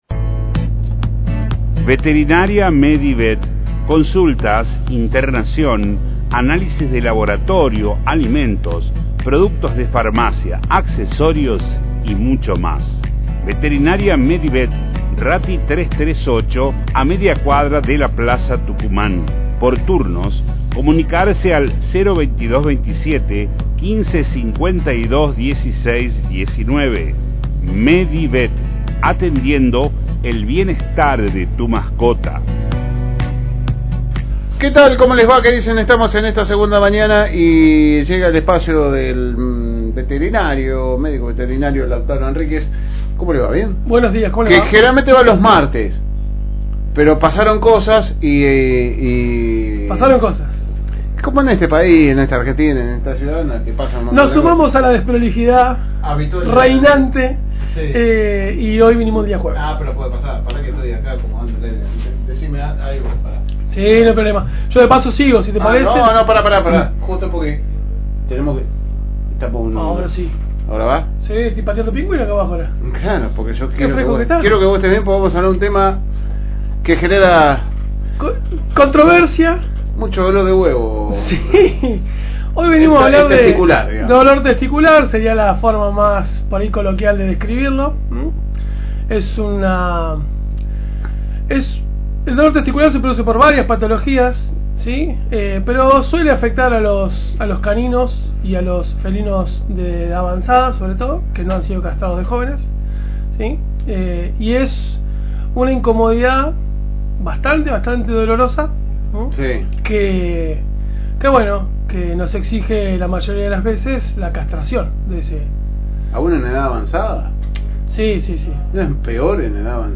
Paso por los estudios de la FM Reencuentro 102.9